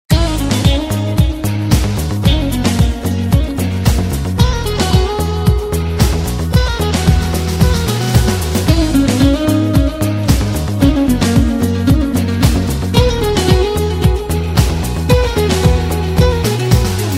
new piano rhythm Ringtone
Bollywood
piano